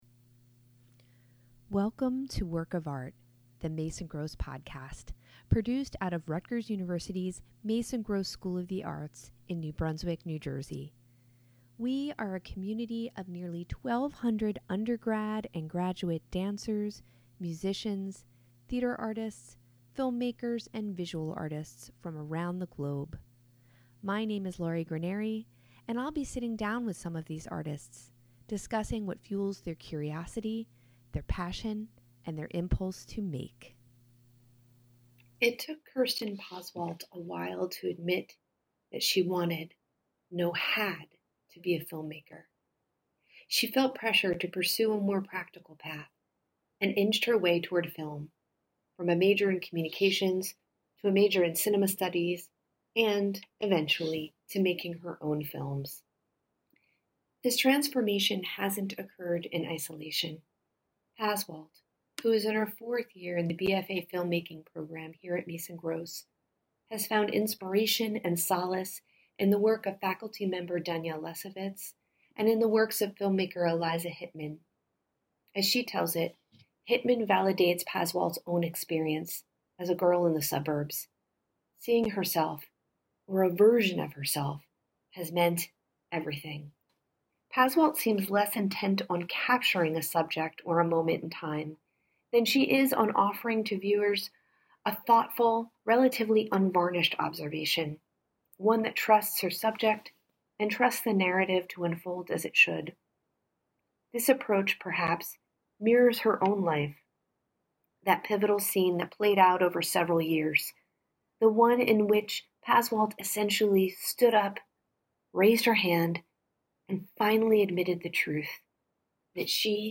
In each episode, we speak with Mason Gross students, faculty, and guest artists, discussing what fuels their curiosity, their passion, and their impulse to make.